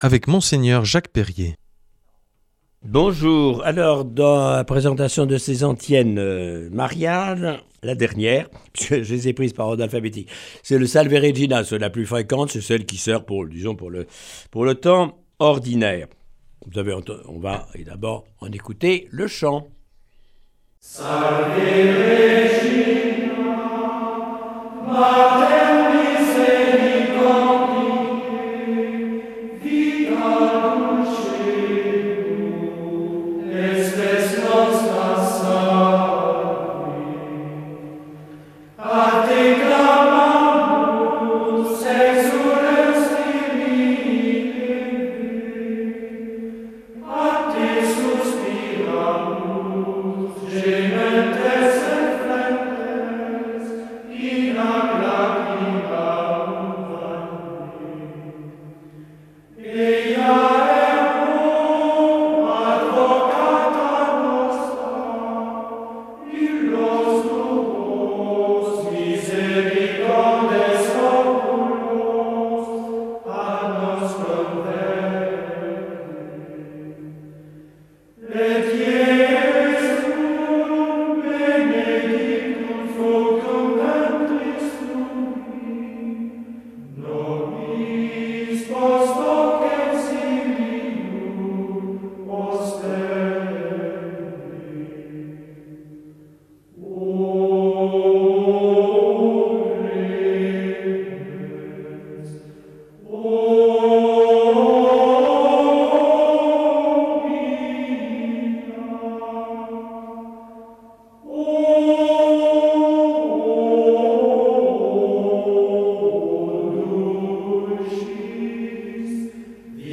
Cette semaine, Mgr Jacques Perrier nous propose des méditations sur des antiennes mariales. Aujourd’hui : Salve Régina.